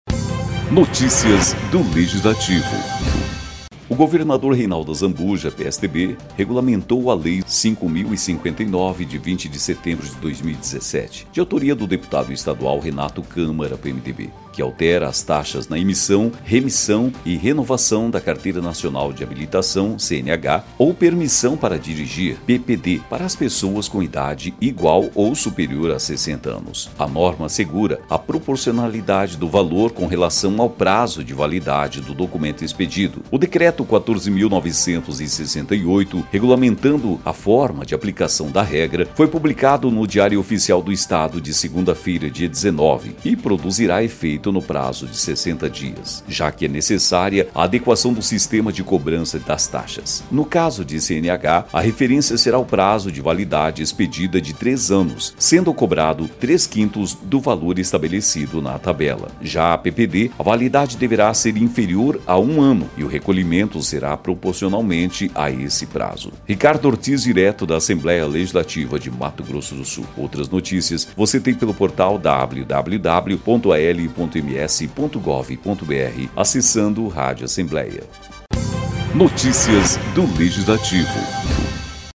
Locução: